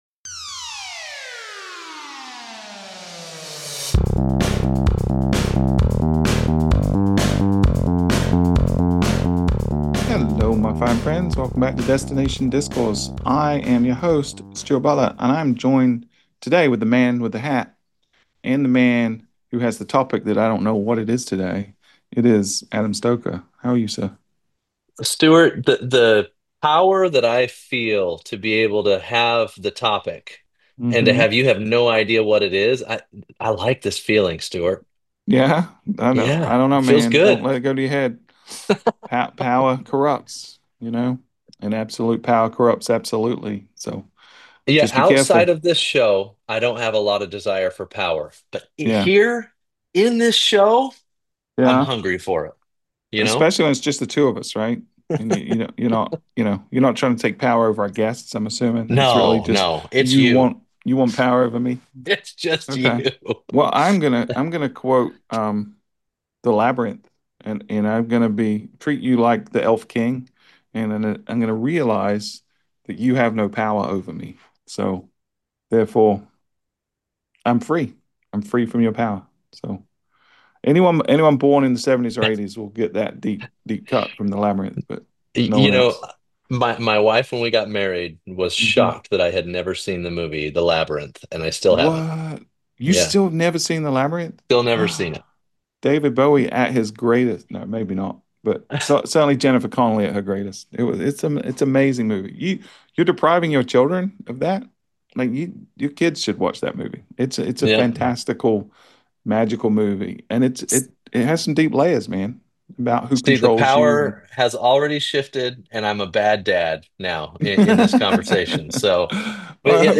It’s raw, honest, and maybe a little uncomfortable, but it’s exactly the kind of conversation the industry needs right now.